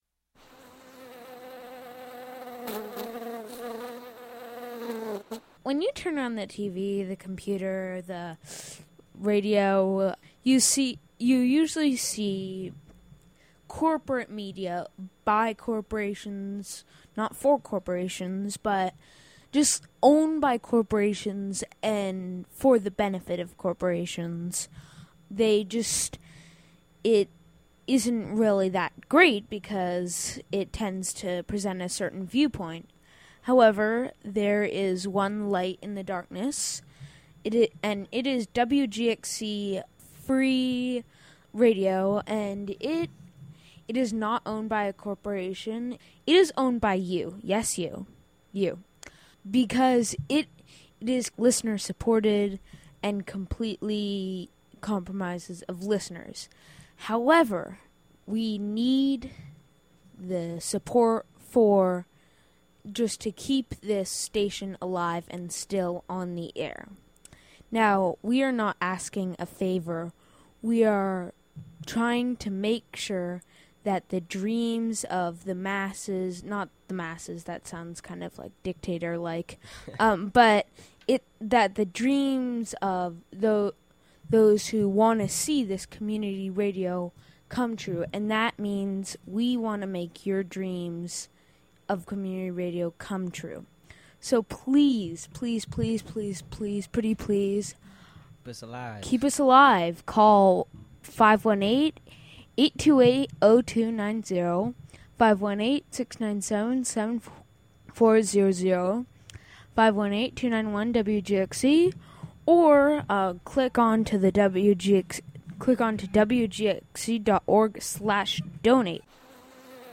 Testimonial for WGXC Bee Your Media Pledge Drive Spring 2012 (Audio)
With :05 bees intro/outro.